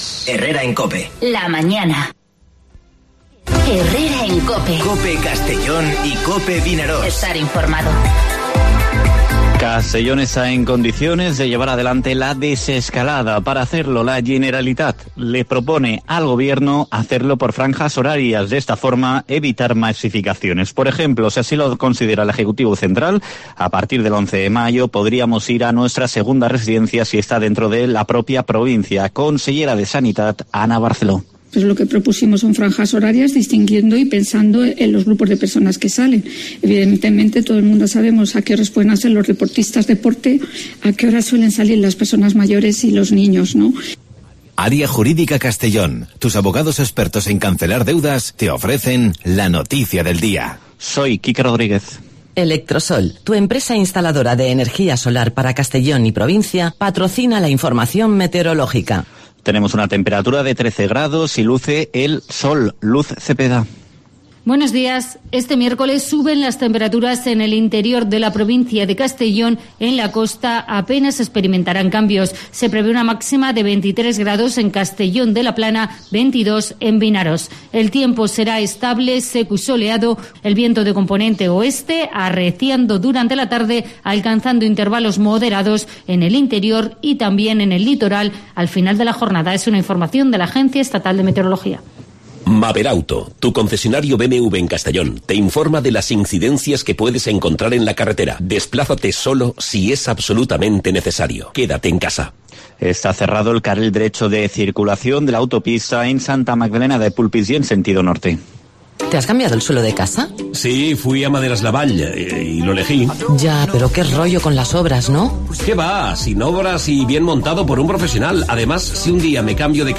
Informativo Herrera en COPE Castellón (29/04/2020)